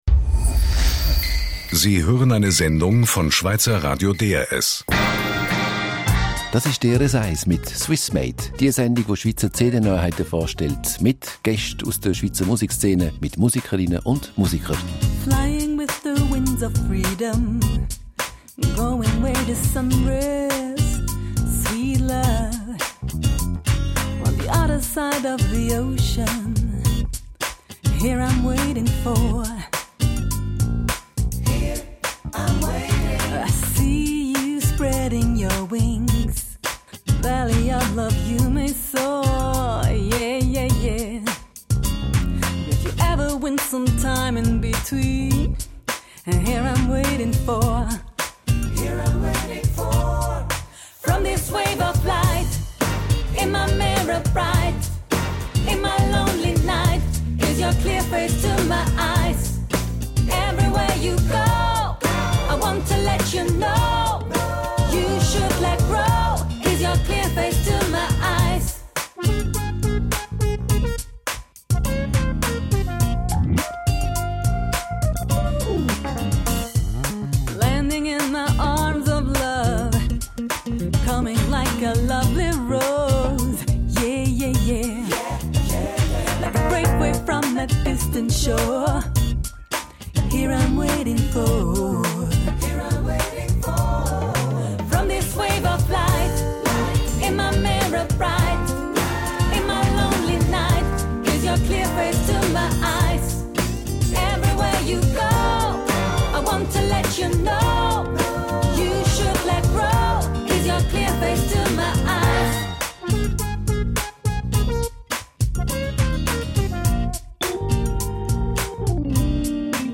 InterviewDRS1.mp3